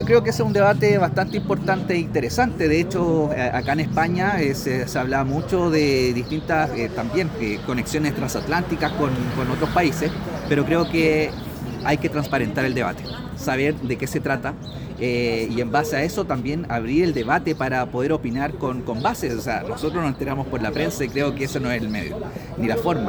En conversación con BioBioChile desde España, el legislador independiente pro DC calificó la discusión como relevante, pero cuestionó la forma en que se ha abordado.